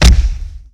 PunchHit6.wav